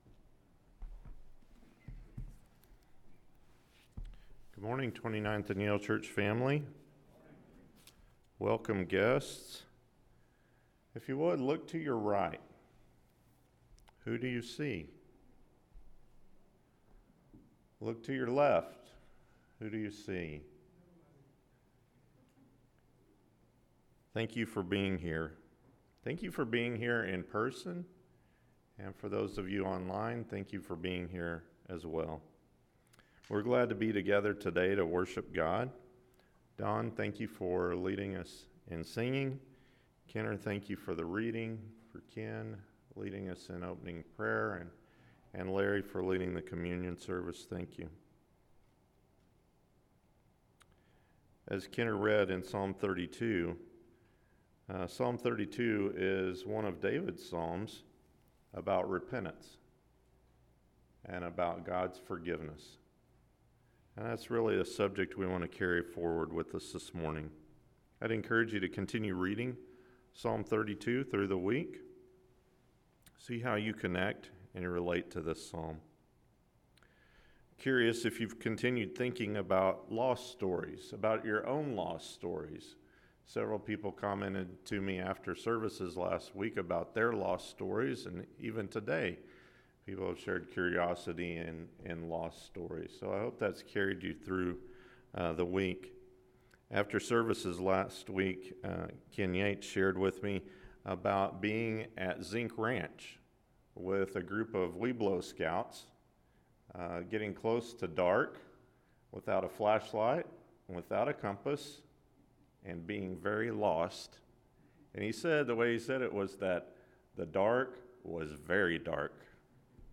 Kingdom Stories: The Prodigal “Sermon” – Luke 15:11-32 – Sermon